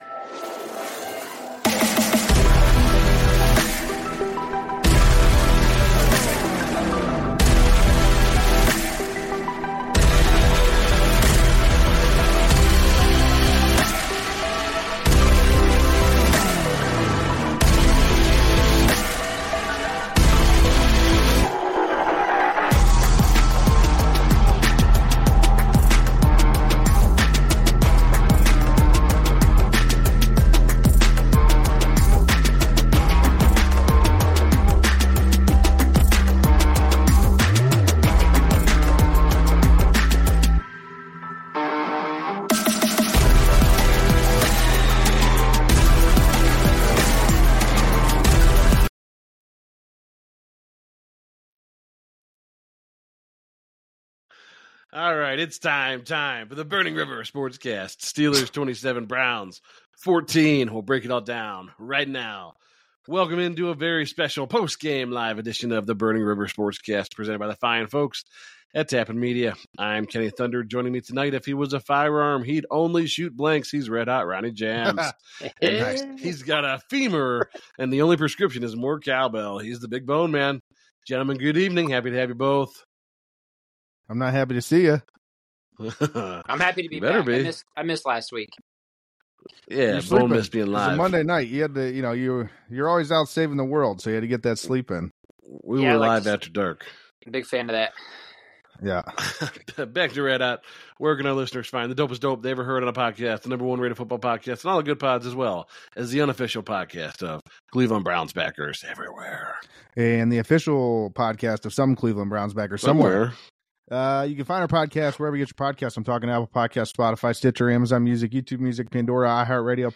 Time for Burning River Sportscast to go live again!